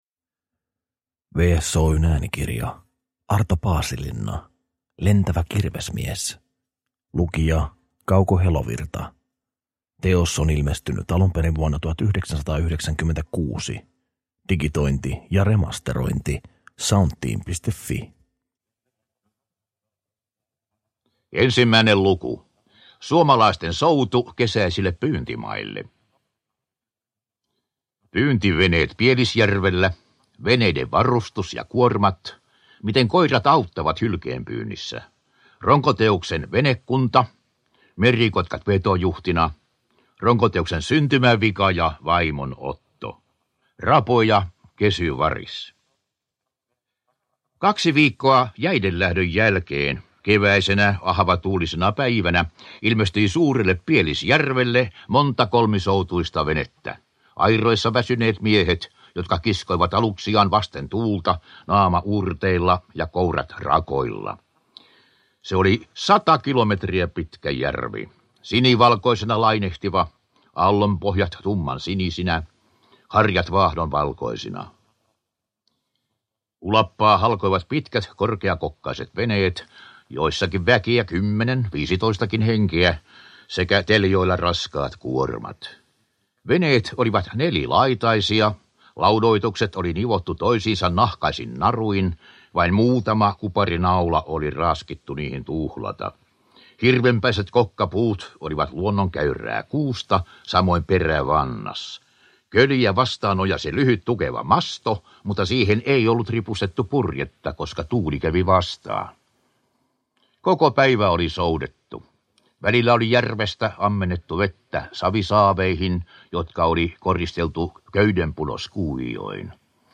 Lentävä kirvesmies – Ljudbok